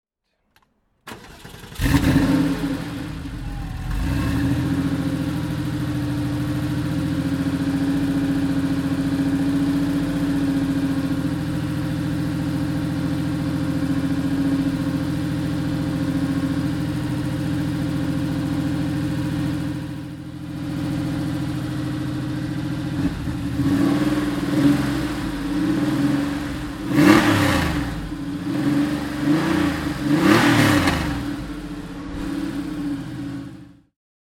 Auch ein Geräuschmuster fehlt nicht.
Porsche 911 (964) Carrera RS (1992) - Starten und Leerlauf